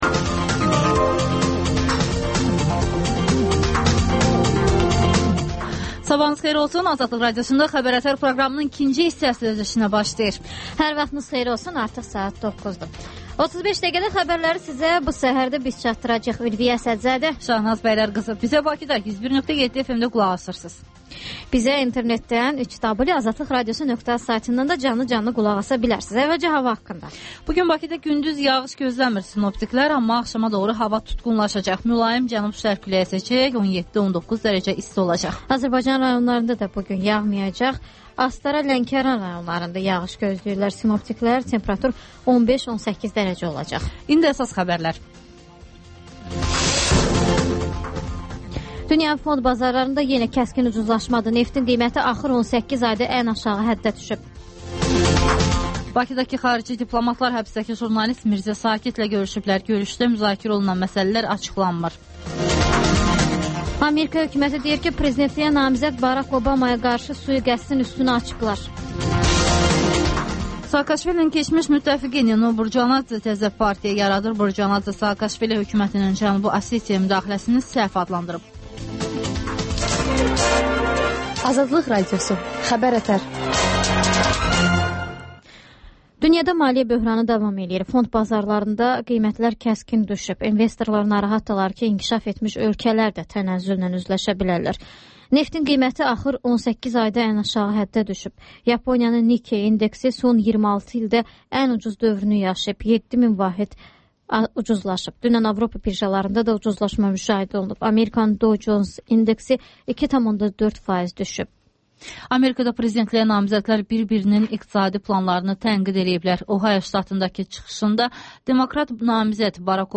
Xəbər-ətər: xəbərlər, müsahibələr, sonra İZ mədəniyyət proqramı